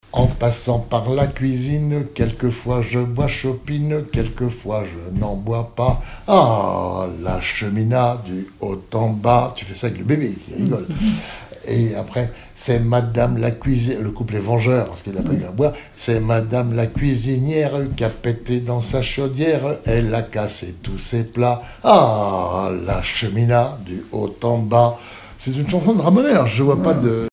Genre brève
Pièce musicale inédite